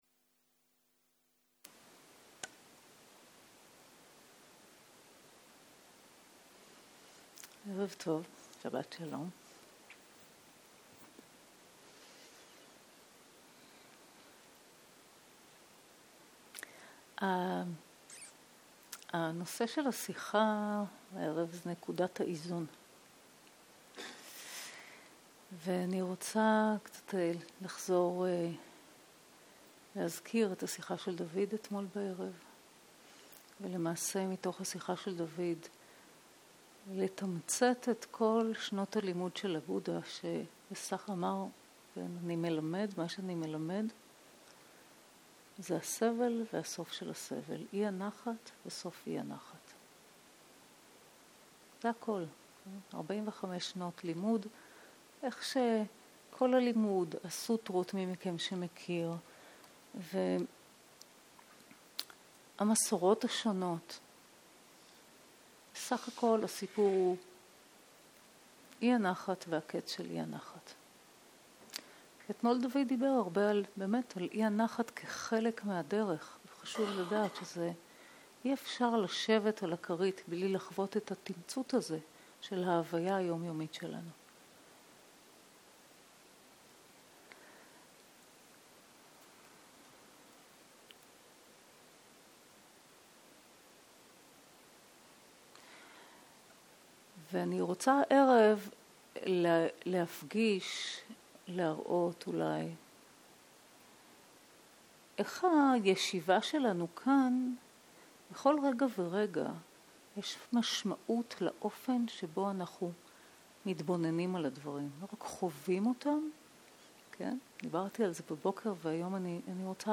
ערב - שיחת דהרמה - נקודת האיזון - הקלטה 7